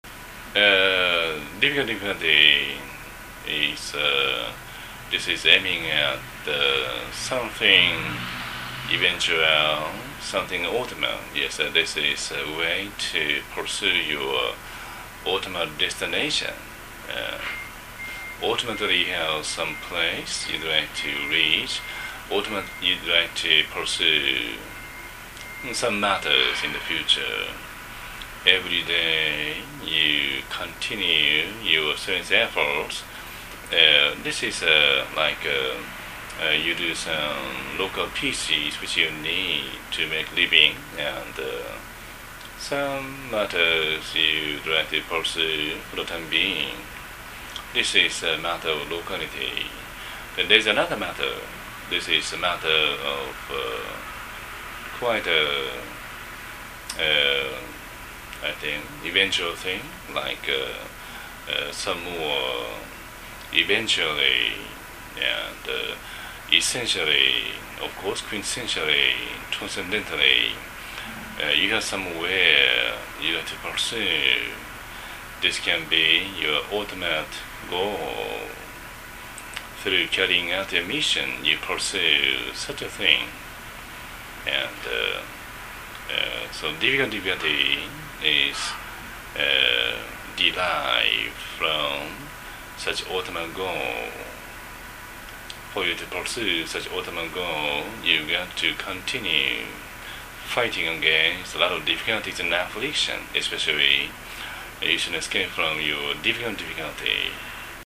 英語音声講義